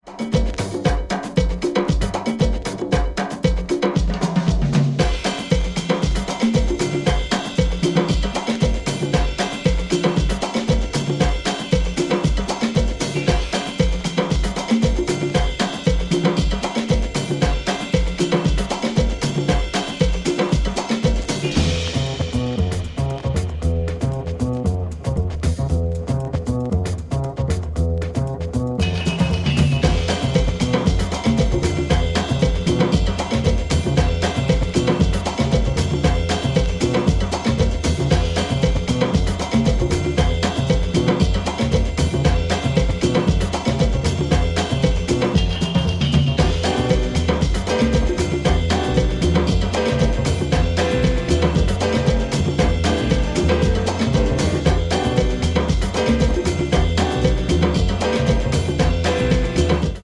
another house tempo jazz cut